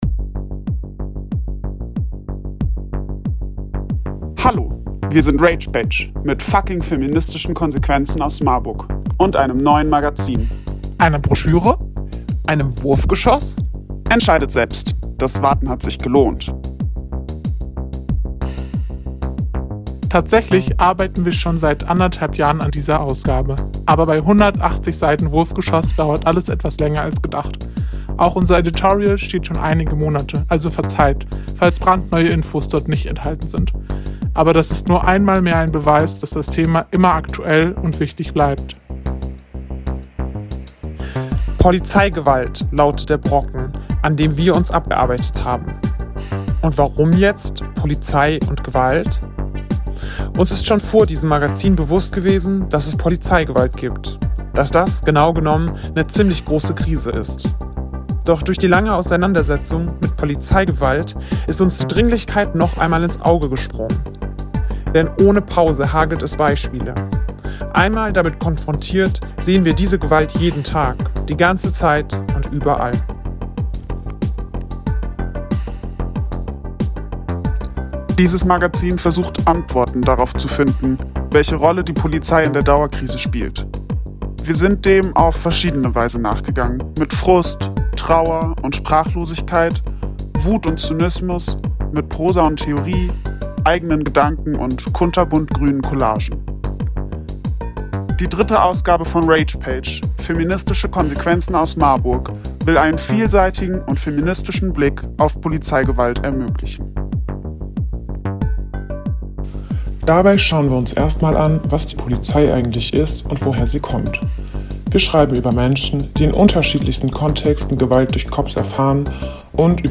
Unser Redebeitrag zum Release der dritten Ausgabe